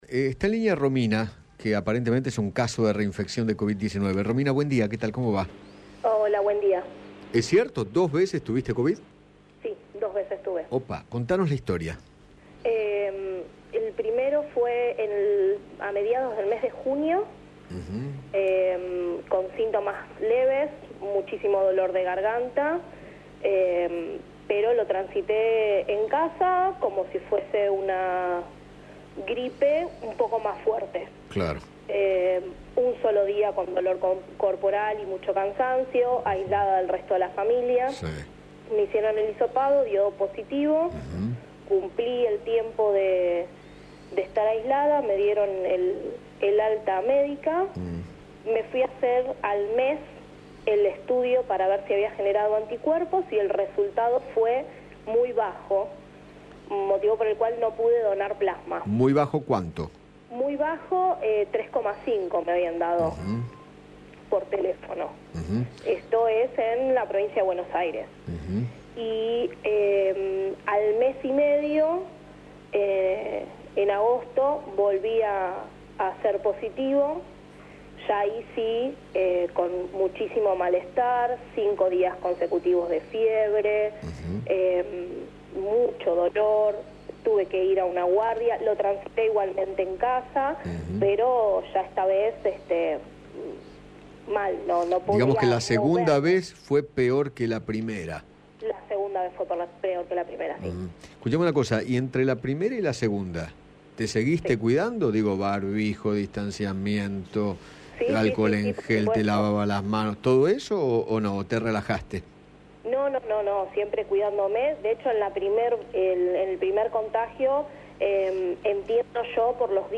El testimonio de una mujer que se reinfectó de covid - Eduardo Feinmann